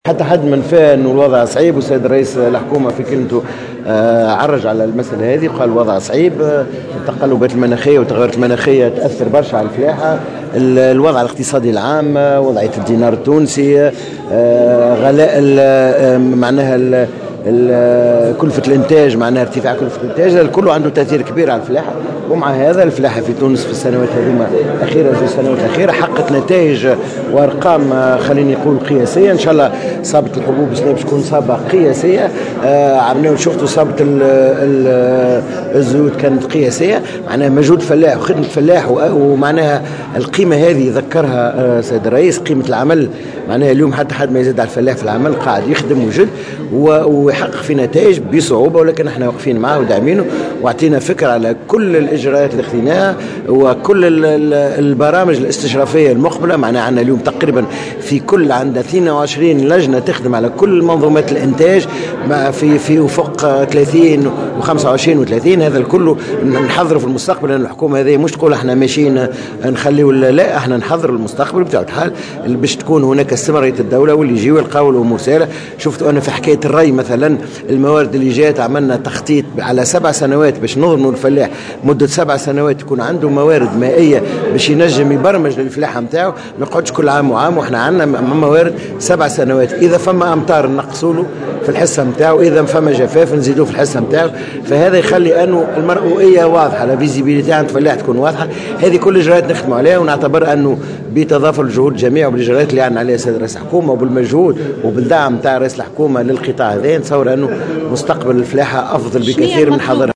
وتوقع الوزير في تصريح لمراسلة "الجوهرة أف أم" تحقيق محاصيل قياسية هذا الموسم بخصوص صابة الحبوب على غرار ما تم تحقيقه في موسم الزيتون، وفق تعبيره.